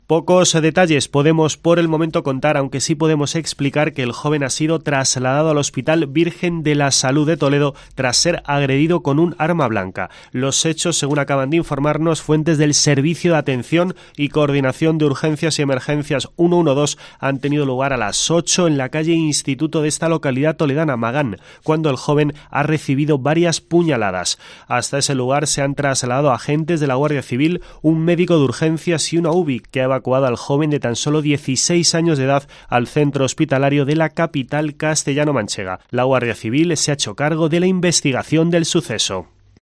Último boletín